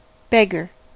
beggar: Wikimedia Commons US English Pronunciations
En-us-beggar.WAV